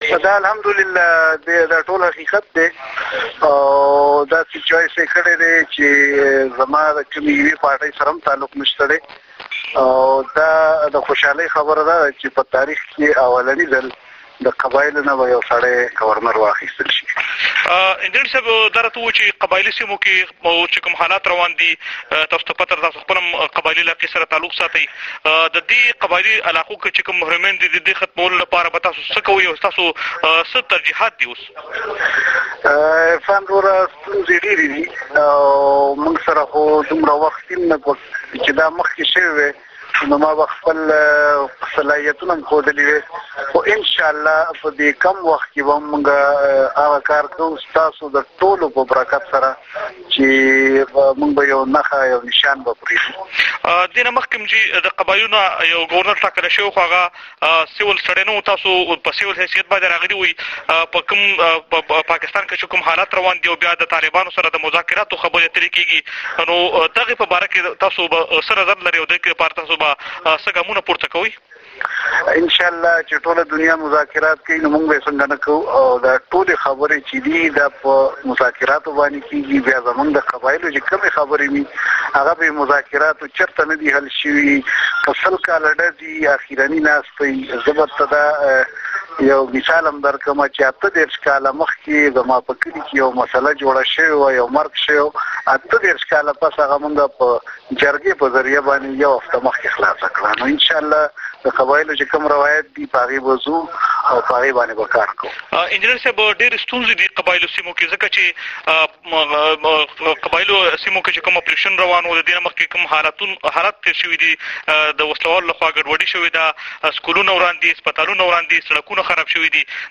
ډیوه ریډیو سره په یوه ځانګري مرکه کې نوي ګورنر ویلي دا وړومبی ځل دی چې د قبایلو نه یو سویلین یا اولسي تن د ګورنر په توګه ټاکل کیږي